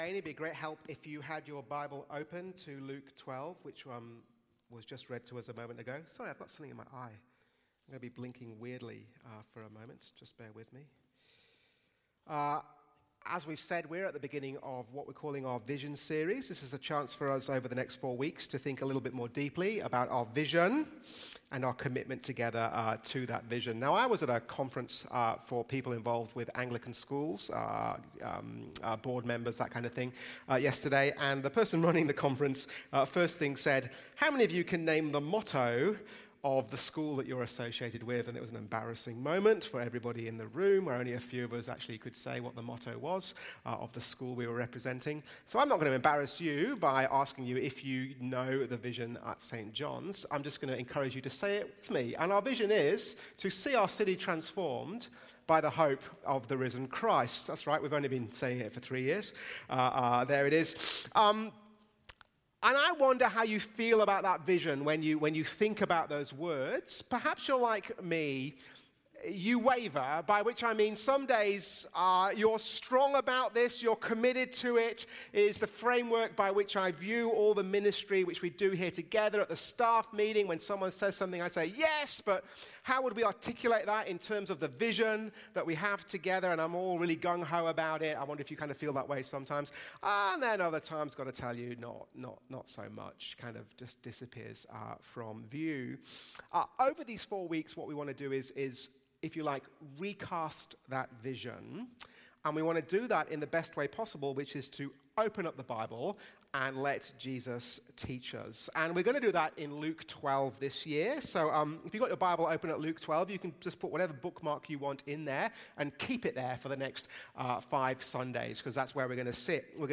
Sunday sermon
from St John’s Anglican Cathedral Parramatta